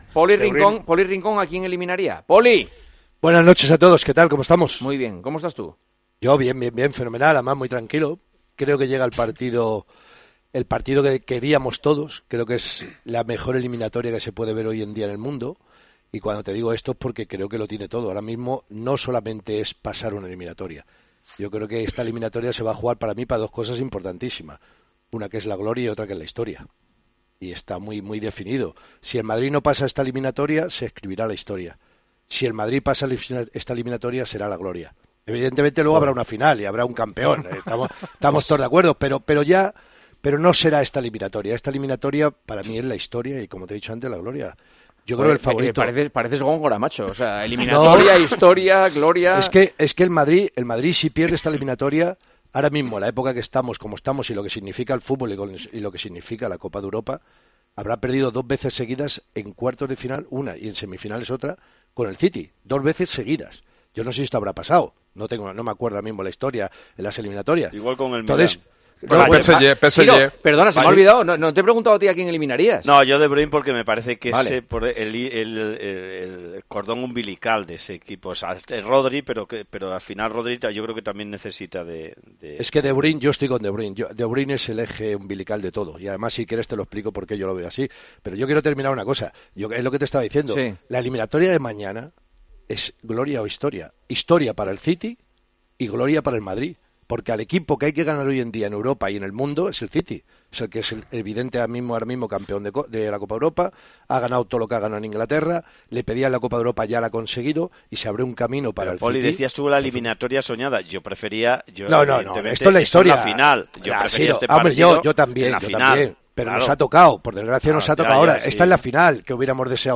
Este martes, Real Madrid y Manchester City jugarán la ida de cuartos de final de la Champions League y Juanma Castaño preguntó a Poli Rincón por sus sensaciones.